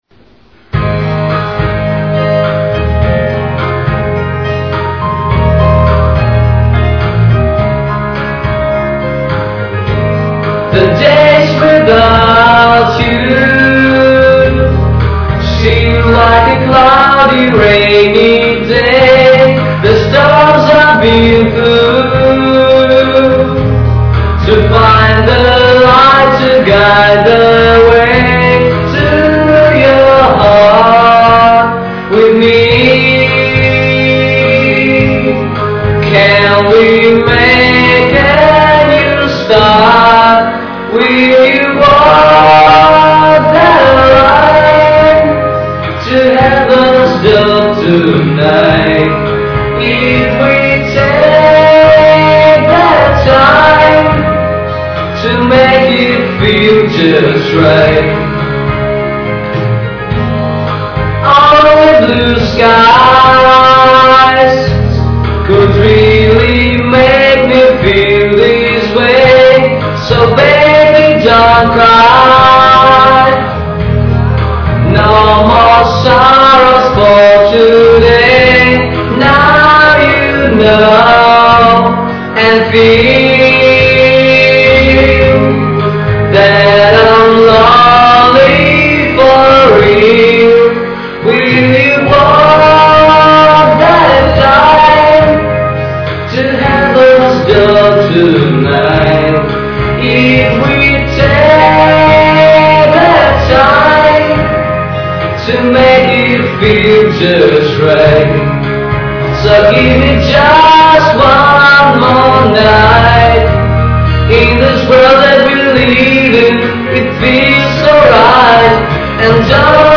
à ìíå ïîíðàâèëñÿ òåìáð
ìäà, òàì âðîäå ïîëîâèíà íîò ìèìî êàññû..
ñëóõ ñòðàäàåò à ãîëîñ íåïëîõ.